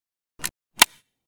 kiparis_holster.ogg